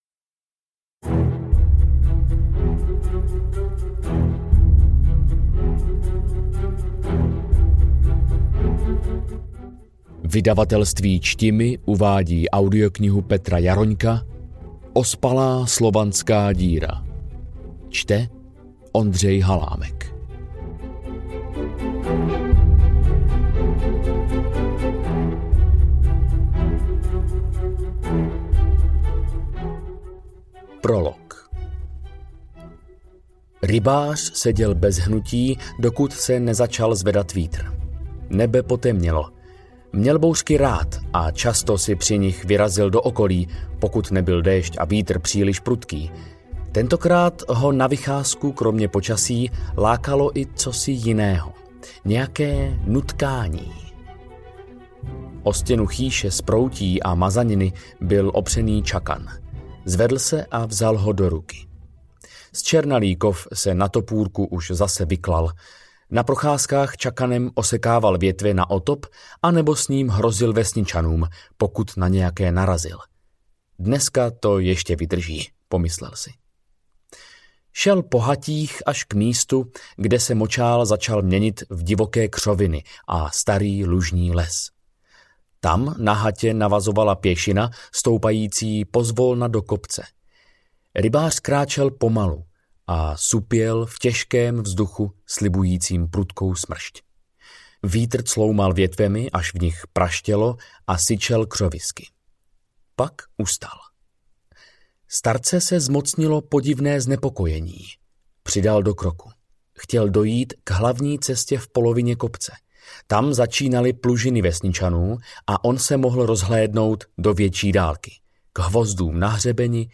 Ospalá slovanská díra audiokniha
Ukázka z knihy